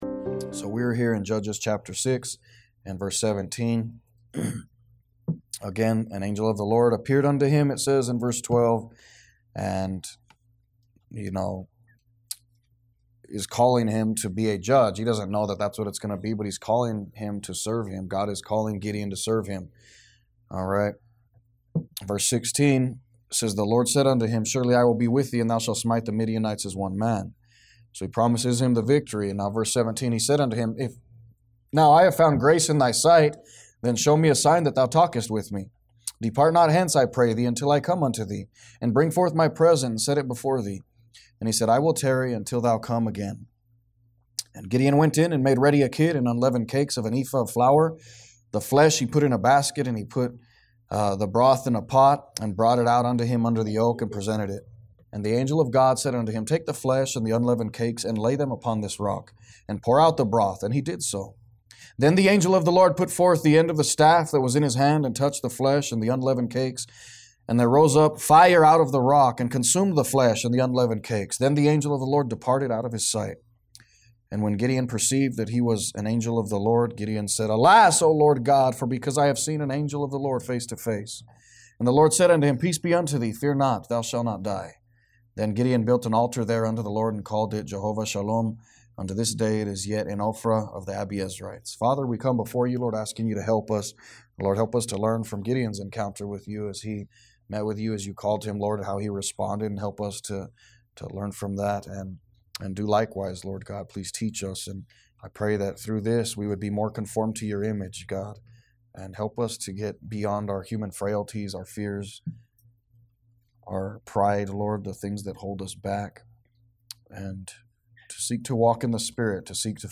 Sermons | Liberty Baptist Church